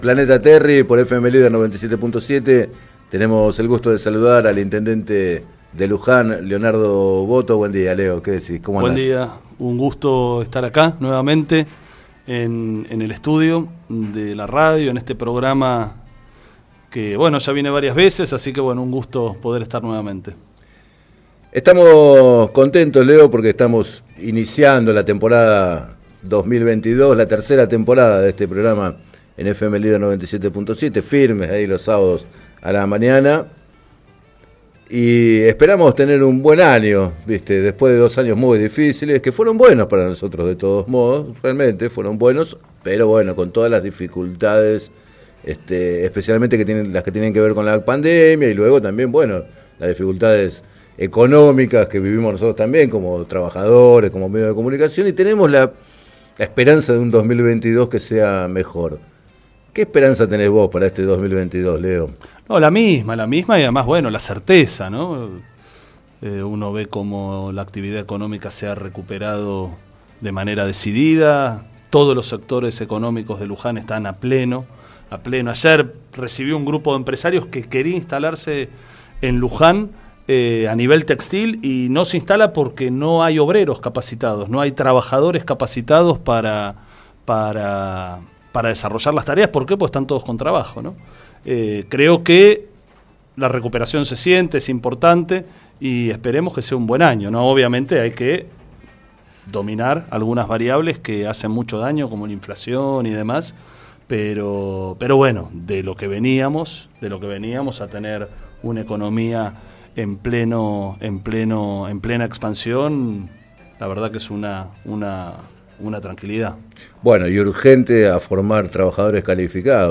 Entrevistado en el programa “Planeta Terri” de FM Líder 97.7, el jefe comunal de Luján afirmó que con estas políticas se brinda una respuesta a miles de familias que no pueden acceder a un lote con servicios y pidió los ediles de Juntos que contribuyan a la paz social.